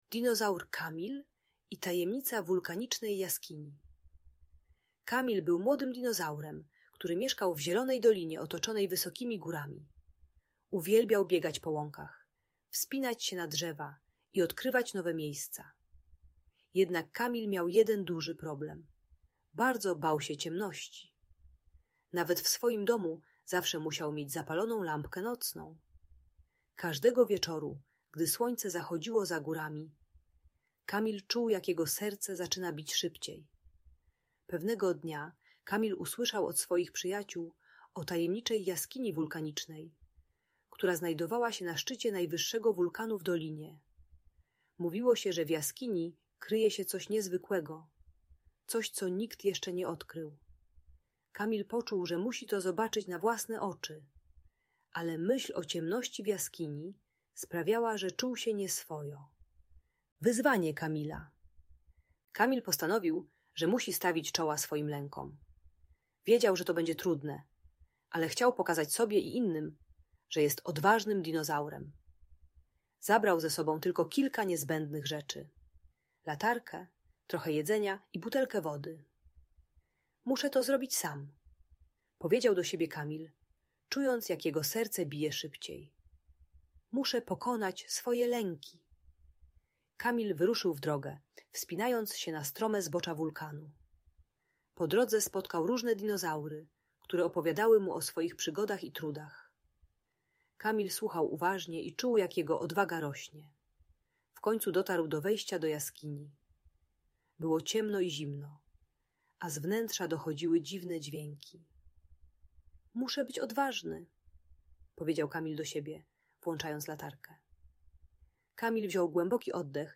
Dinozaur Kamil i Tajemnica Wulkanicznej Jaskini - Lęk wycofanie | Audiobajka